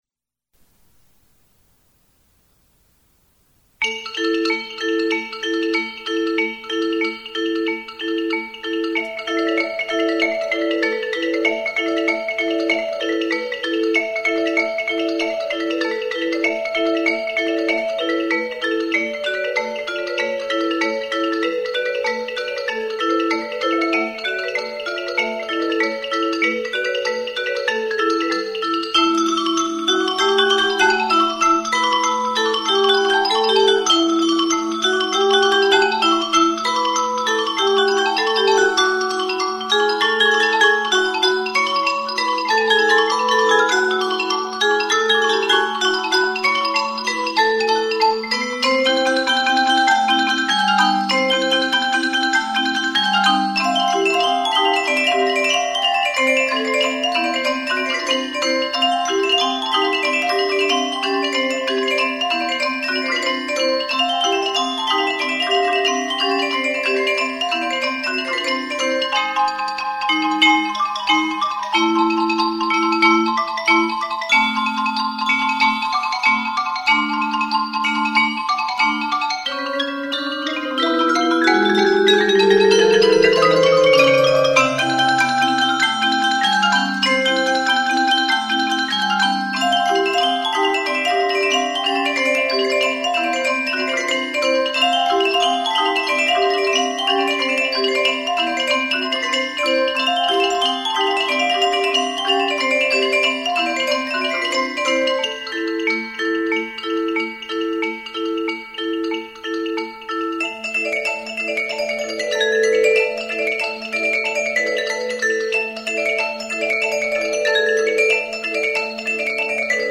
Four Pieces from the Gayane Ballet Suite (for mallet percussion quartet)
From live performance
bells
xylophone/marimba
vibraphone
These recordings were derived primarily from the premiere performance of the music in 1992 at a public recital.
The familiar Lezghinka, a fast and furious dance bordering on the barbaric, makes full use of the vivid color of music from the Caucasus region.
KEYBOARD PERCUSSION ENSEMBLE